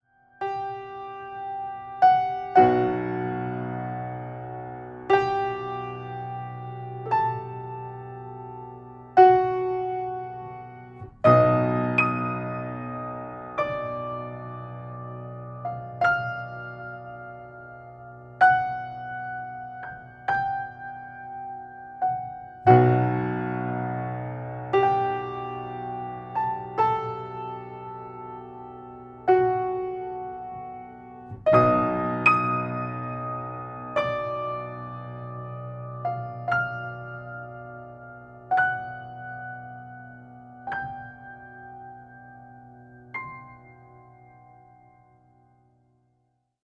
10 pieces for solo piano.